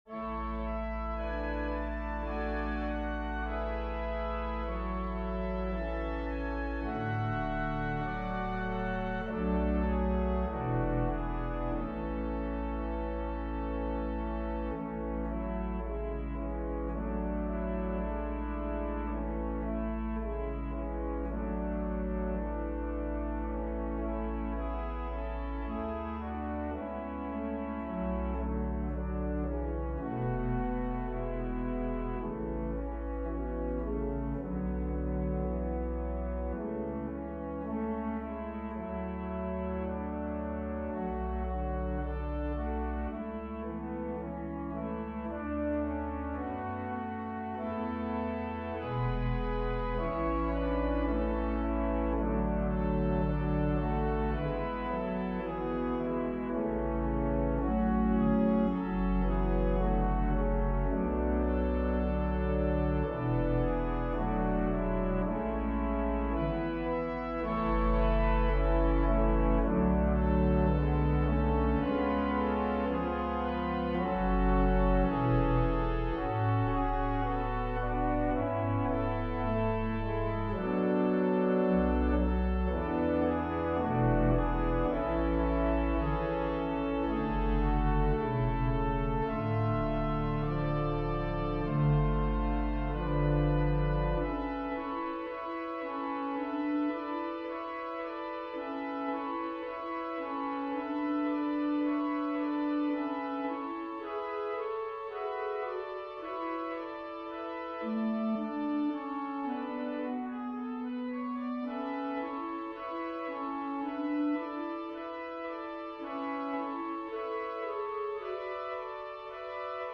Organ/Organ Accompaniment
Voicing/Instrumentation: Organ/Organ Accompaniment We also have other 13 arrangements of " I Will Walk with Jesus ".